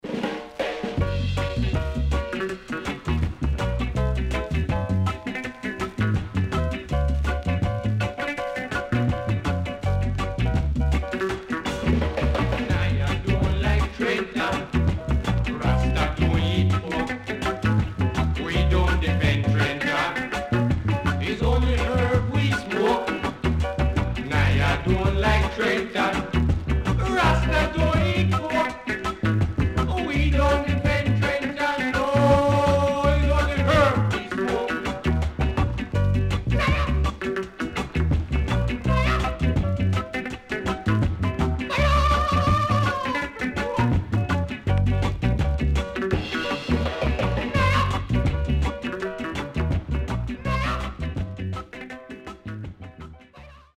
HOME > REGGAE / ROOTS  >  EARLY REGGAE
69年.W-Side Nice Early Reggae
SIDE A:所々チリノイズがあり、少しプチノイズ入ります。